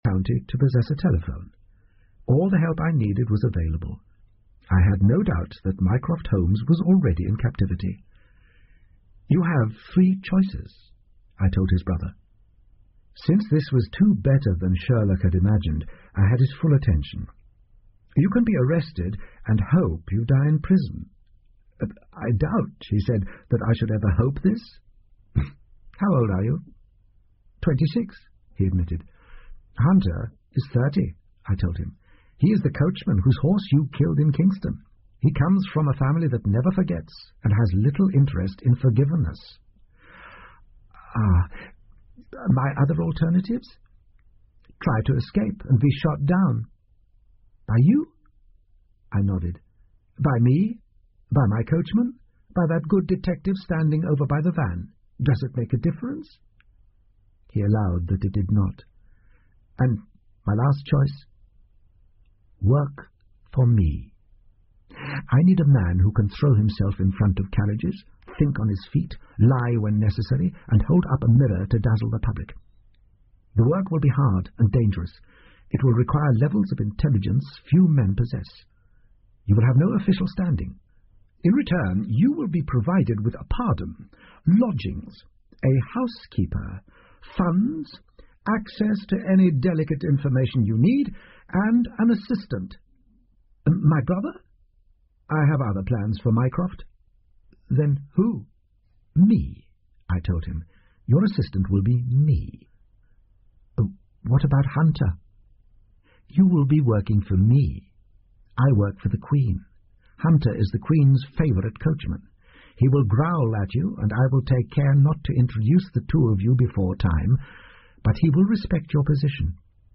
福尔摩斯广播剧 Cult-The Spy Retirement 6 听力文件下载—在线英语听力室